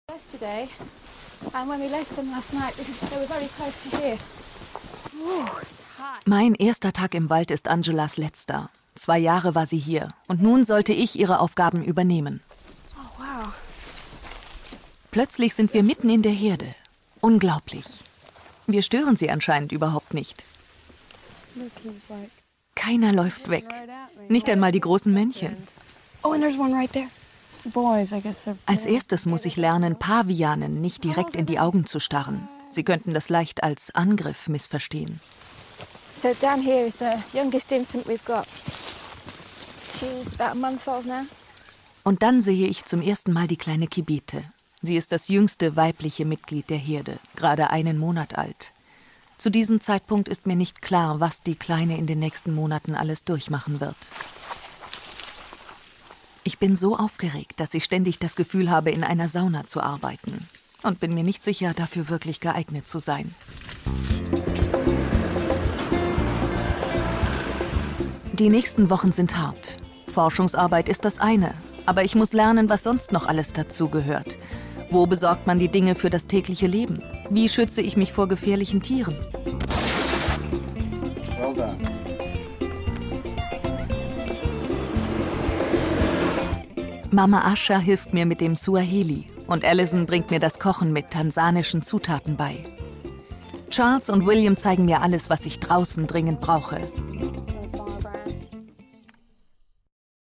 Mittel plus (35-65)